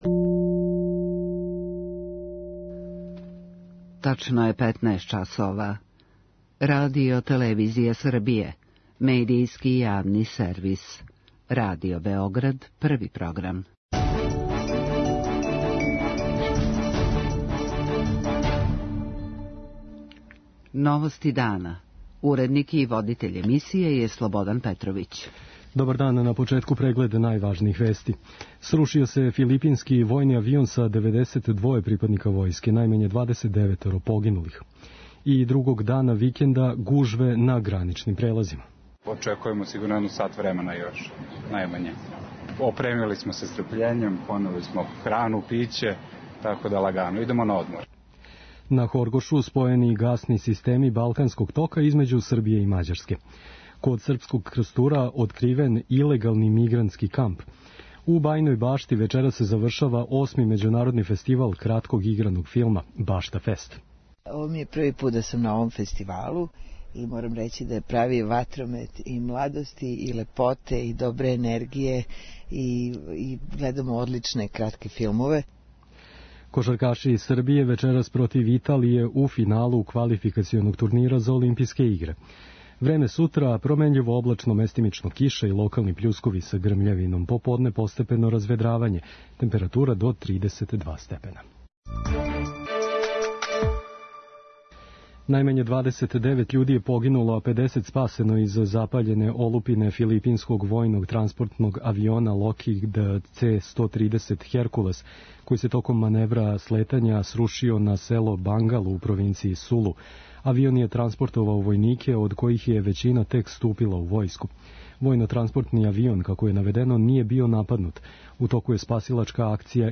Искључена је могућност терористичког напада, а филипинске власти кажу како је приоритет да се утврди узрок пада летелице. преузми : 5.66 MB Новости дана Autor: Радио Београд 1 “Новости дана”, централна информативна емисија Првог програма Радио Београда емитује се од јесени 1958. године.